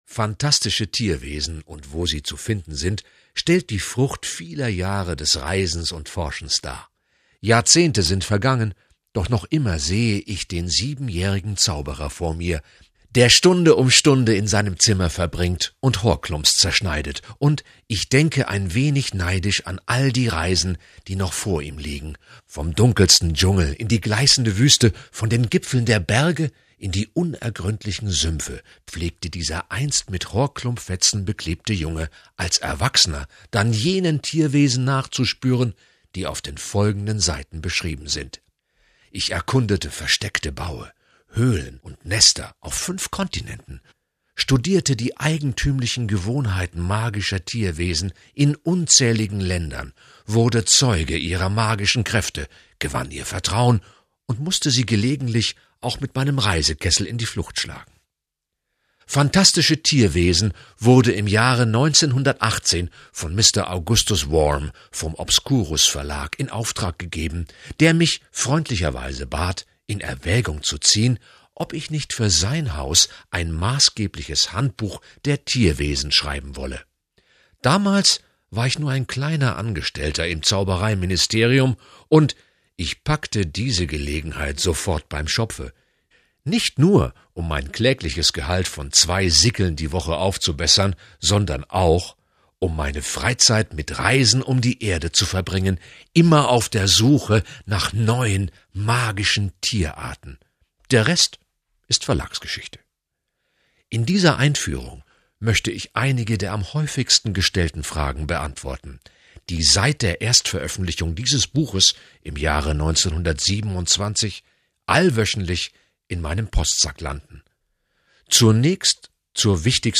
Leseprobe Phantastische Tierwesen